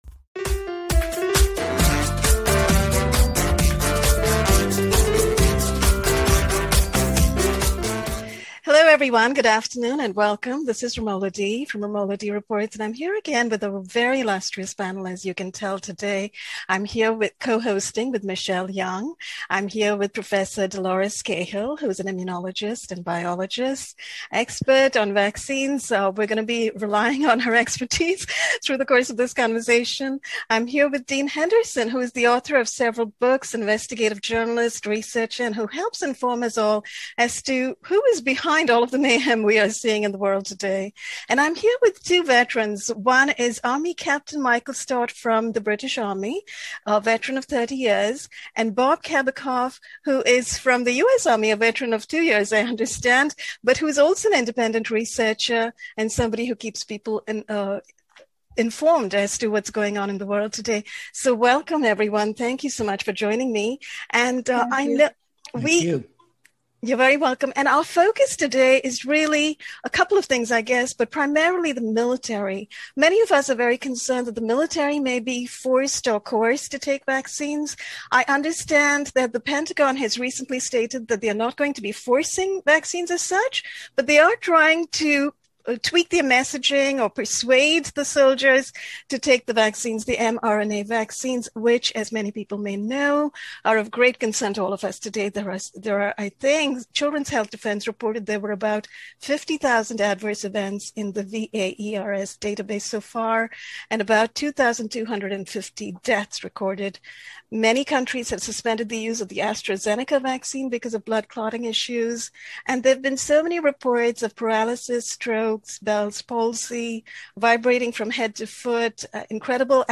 Impassioned and inspiring news panel